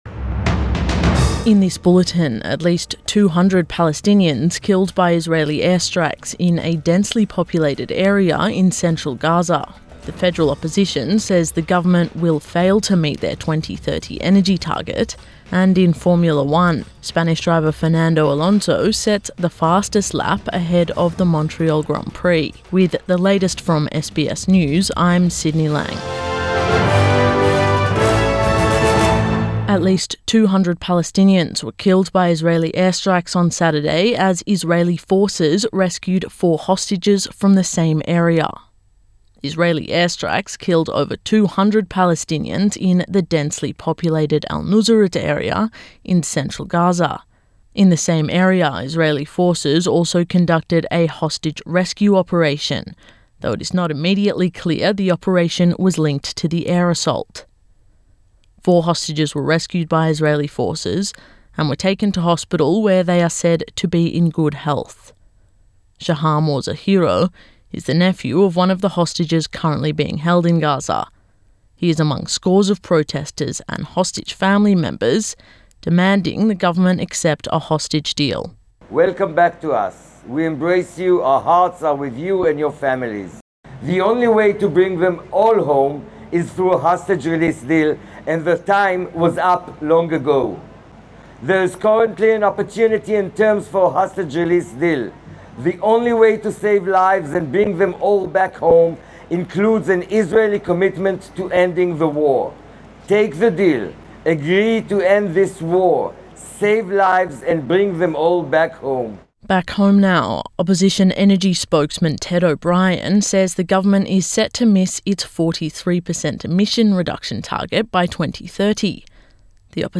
Morning News Bulletin 8 June 2024